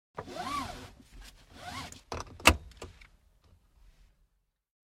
Щелчок и звук натяжения ремня безопасности